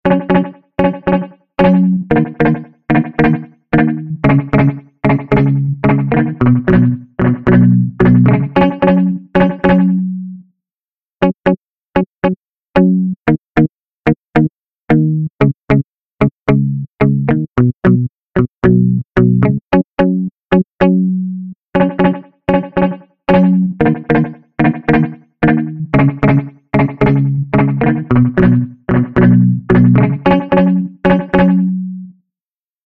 Tube Amp-style Spring Reverb
Spring | Electric Guitar | Preset: Metallic Slapback
Spring-Eventide-Guitar-Metallic-Slapback.mp3